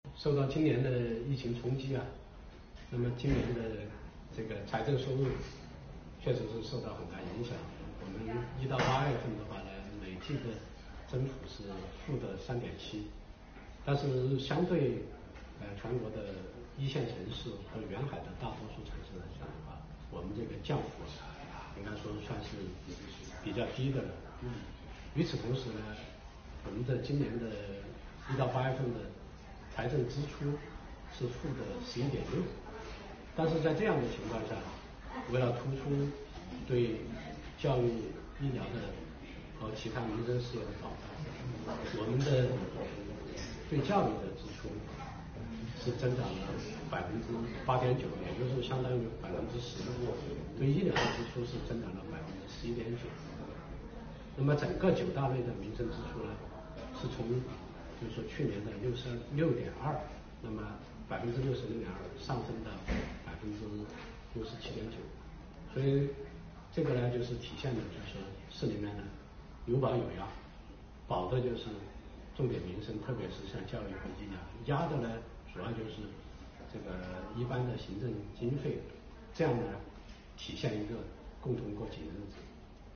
9月25日上午，深圳市财政局党组书记、局长汤暑葵做客新闻频率（先锋898）《民心桥》节目，向市民朋友晒出深圳先行示范区建设的“民生账本”。